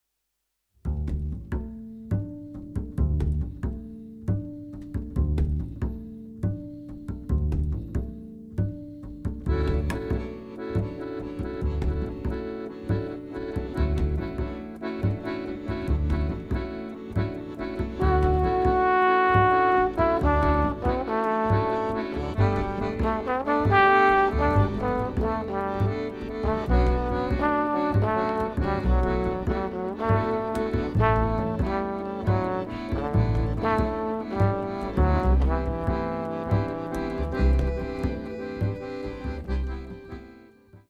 Accordéon / Ténor Lyrique
Trombone
Contrebasse
au Studio Les Tontons Flingueurs (Renaison - France)